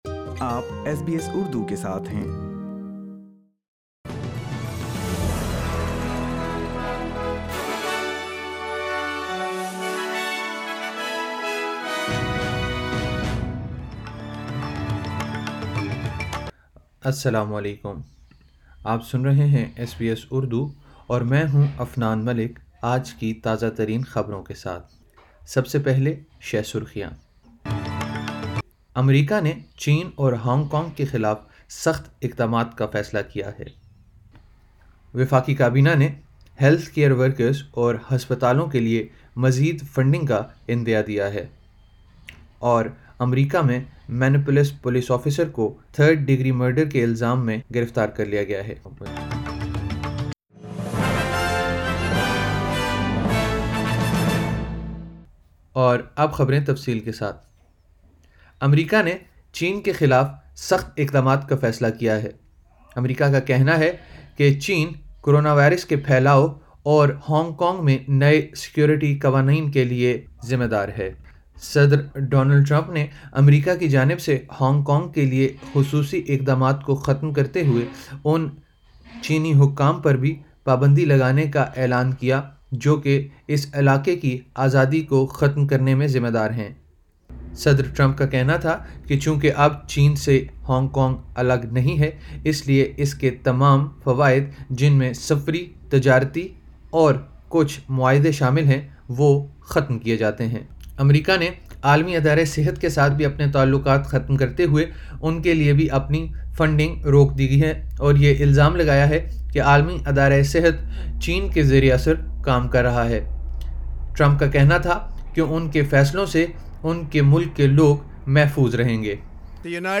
ایس بی ایس اردو خبریں 30 مئی 2020